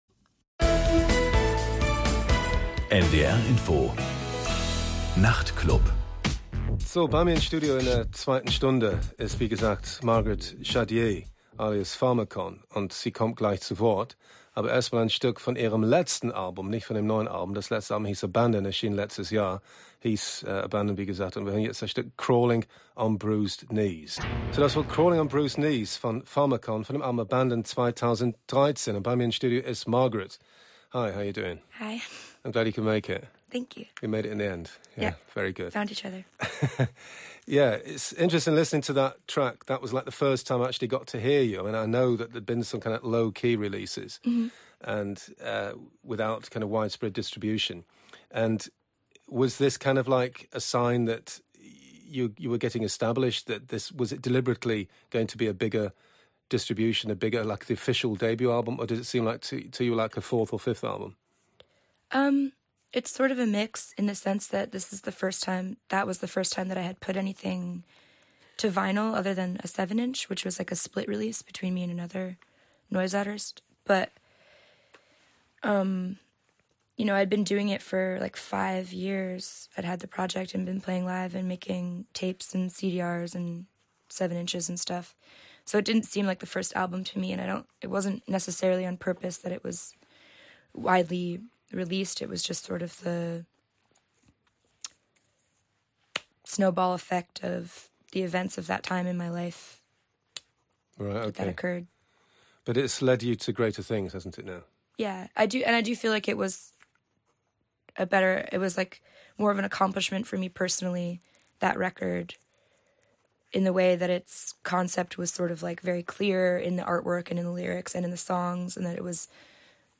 Interviews
Die Interviews lassen sich in 3 Arten klassifizieren: – Live-Studiogast.